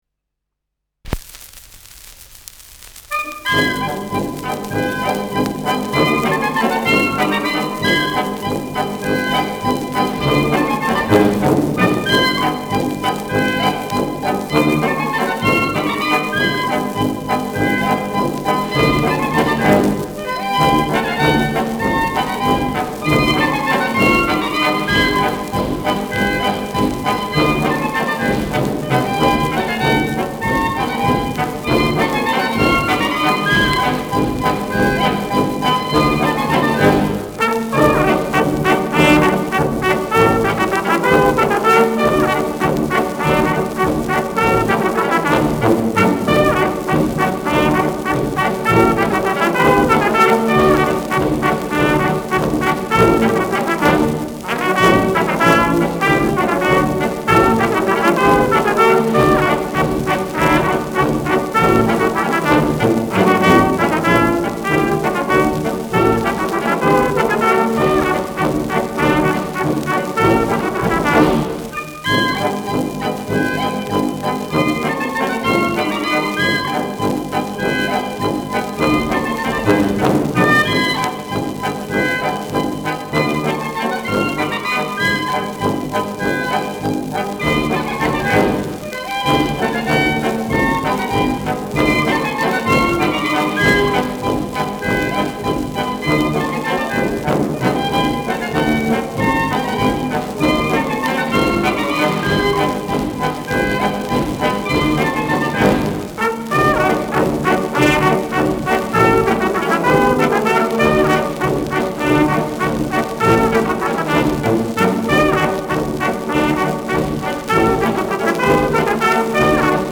Schellackplatte
leichtes Rauschen : Knistern